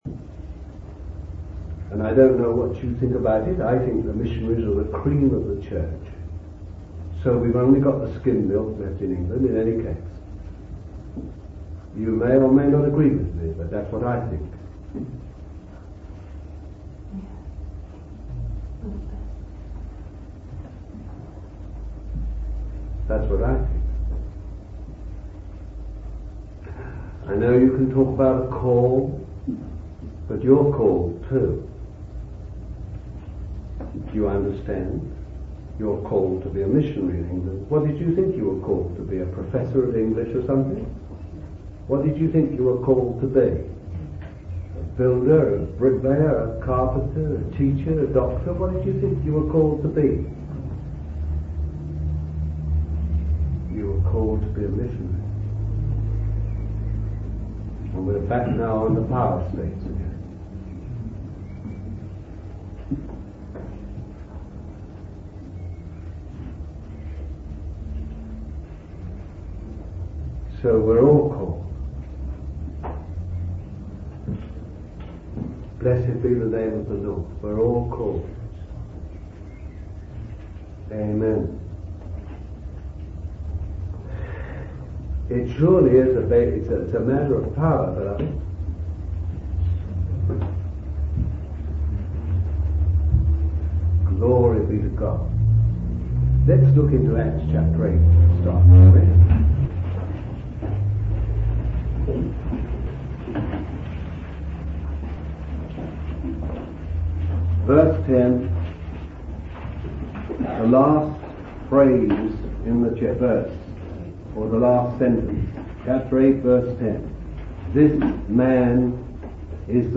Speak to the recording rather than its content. This is session 2 of a two-part series preached in Exeter, United Kingdom in 1972. It deals with the power of God as it impacts the lives of sinners.